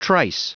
Prononciation du mot trice en anglais (fichier audio)
Prononciation du mot : trice